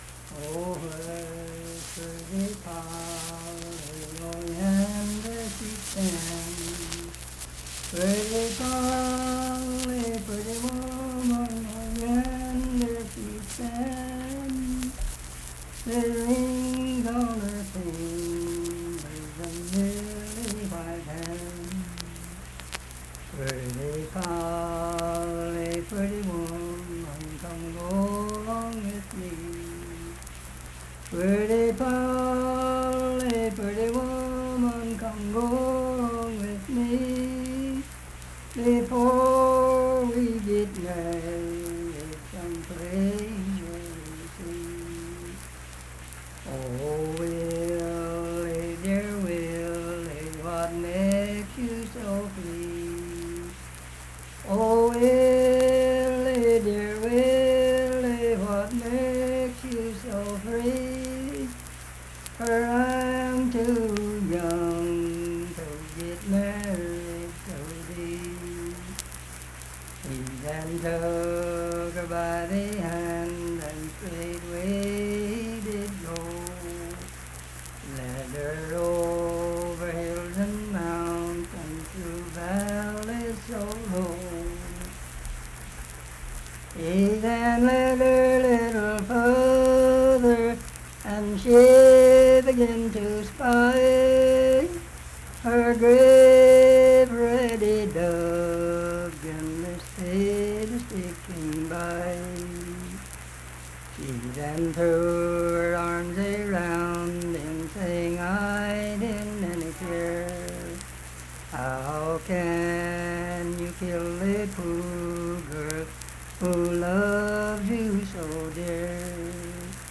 Unaccompanied vocal music
Verse-refrain 8(6-8).
Voice (sung)
Harts (W. Va.), Lincoln County (W. Va.)